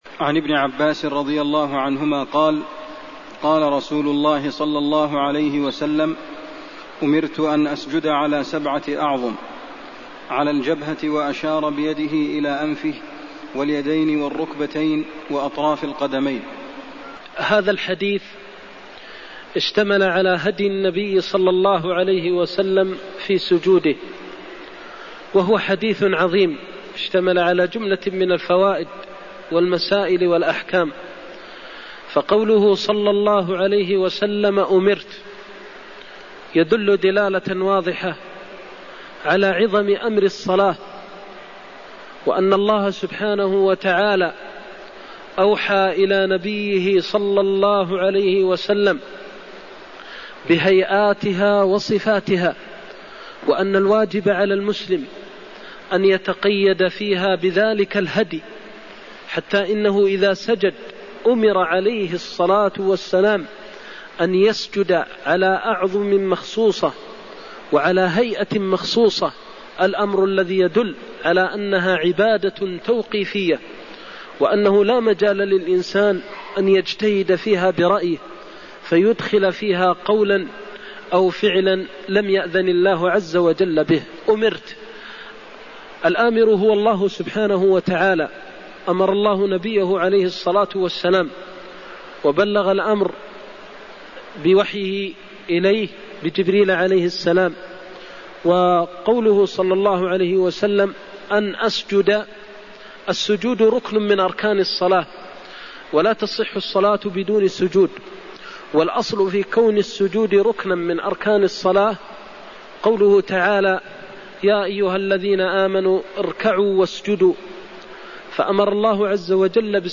المكان: المسجد النبوي الشيخ: فضيلة الشيخ د. محمد بن محمد المختار فضيلة الشيخ د. محمد بن محمد المختار أمرت أن أسجد على سبعة أعظم (81)